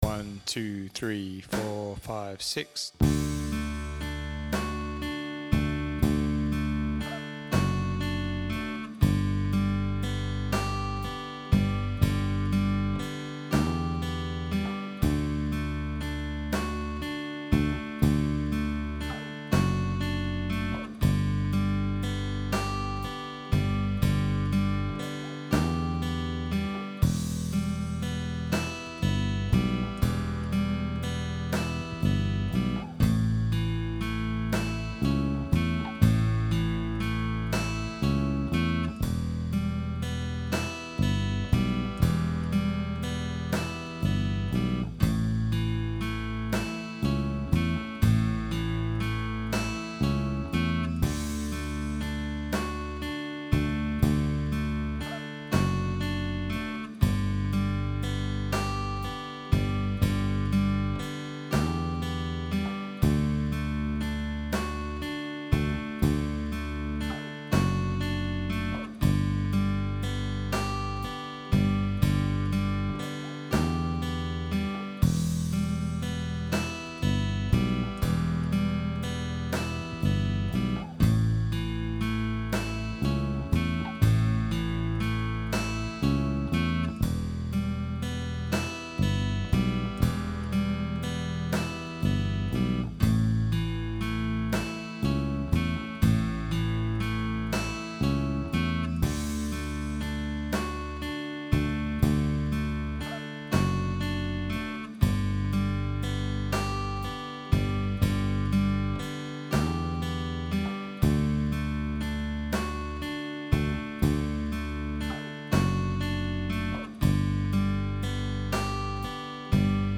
Everyone Feels Backing Track | Download